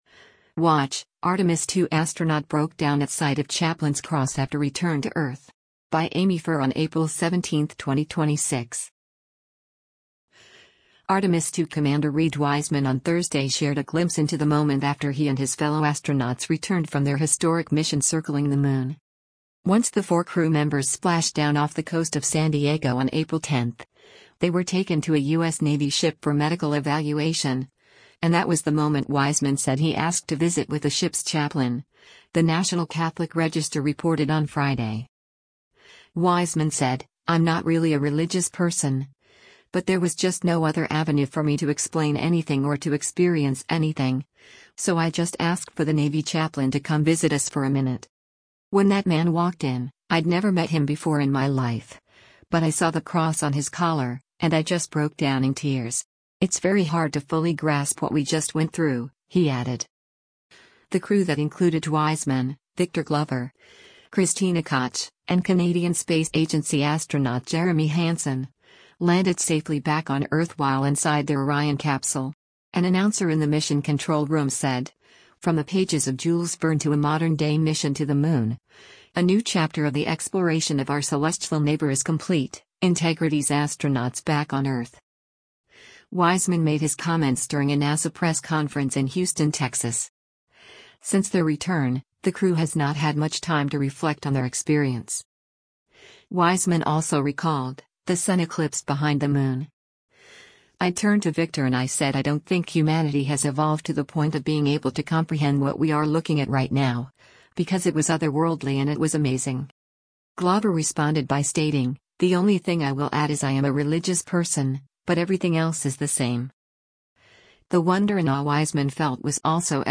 Wiseman made his comments during a NASA press conference in Houston, Texas.